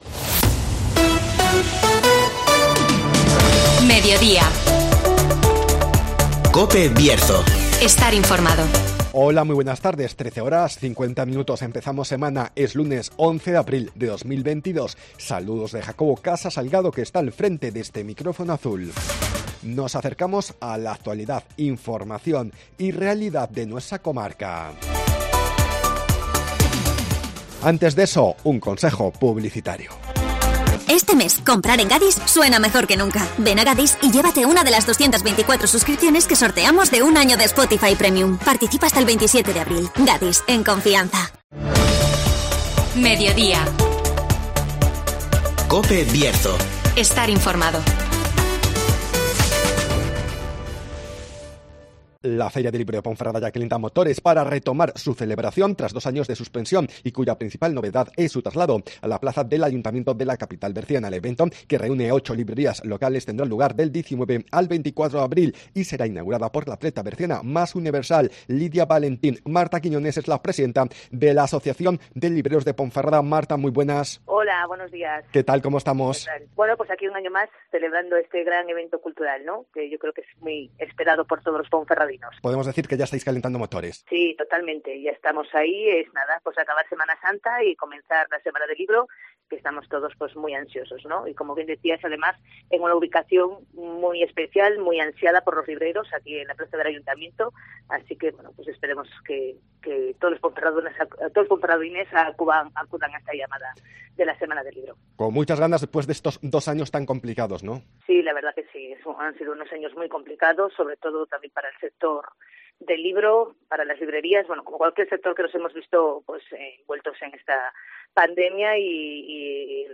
Ponferrada recupera la Feria del Libro (Entrevista